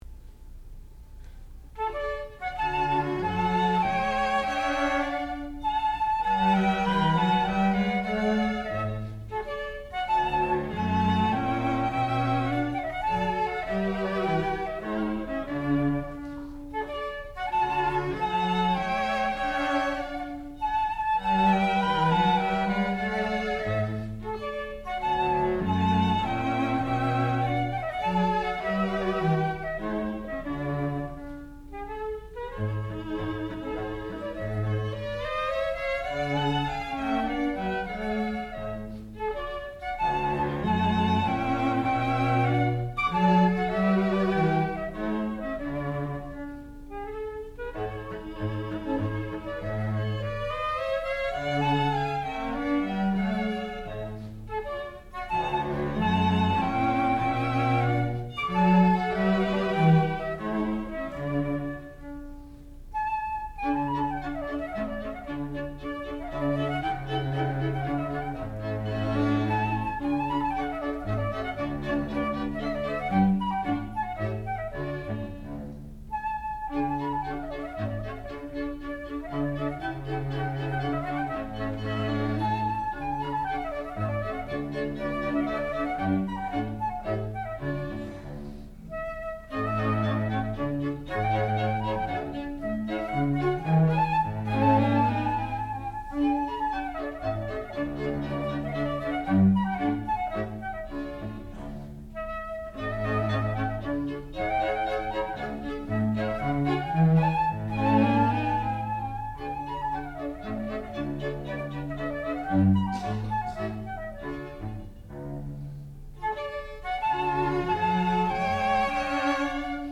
sound recording-musical
classical music
The Shepherd Chamber Players (performer).